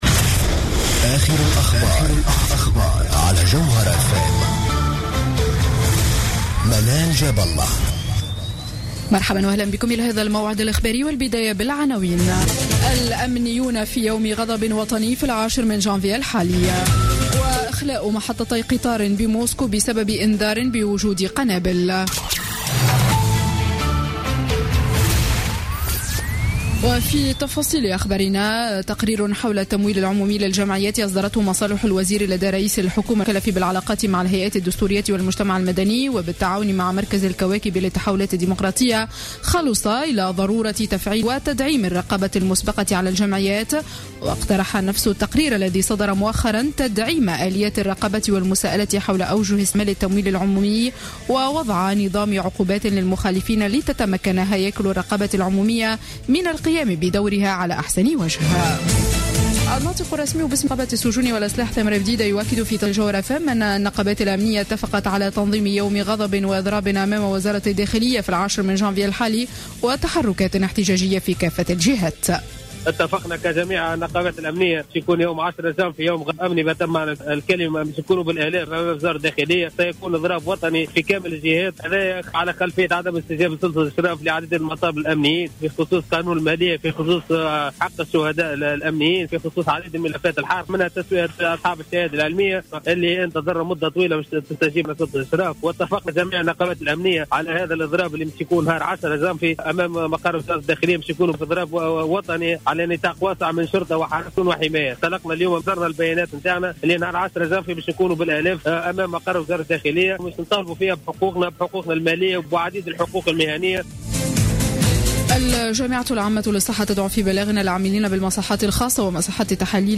نشرة أخبار السابعة مساء ليوم الجمعة غرة جانفي 2016